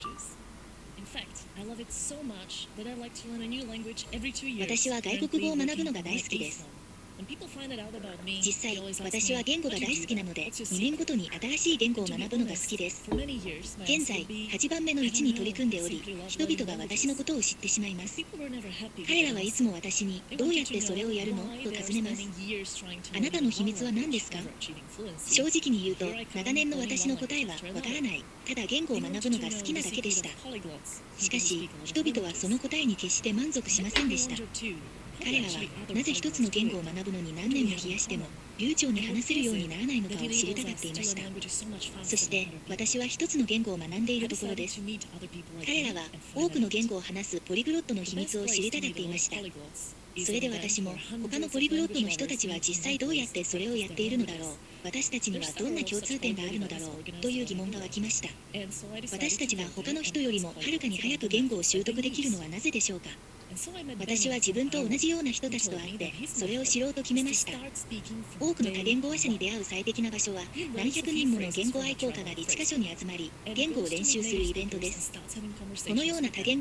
本モードの場合も、TEDの音声を翻訳させてみた。
※翻訳中のアプリ画面と、イヤホン音声の録音データを掲載している。